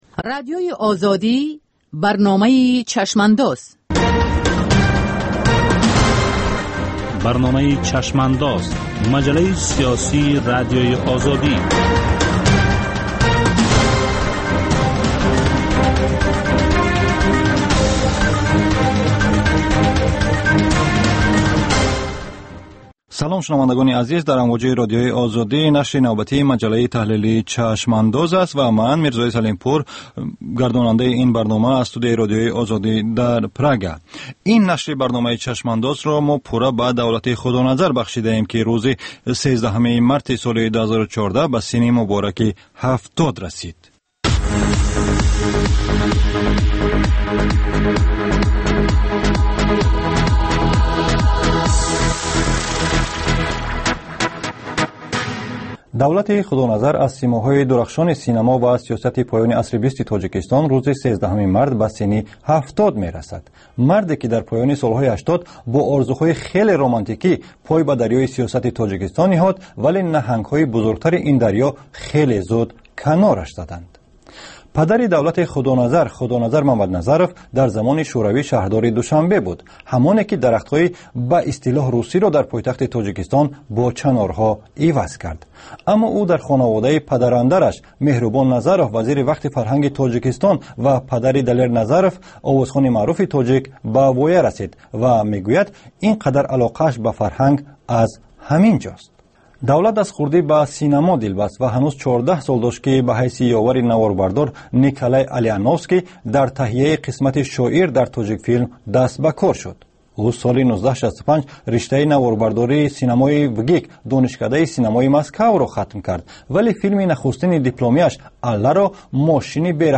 Баррасии рӯйдодҳои сиёсии Тоҷикистон, минтақа ва ҷаҳон дар гуфтугӯ бо таҳлилгарон.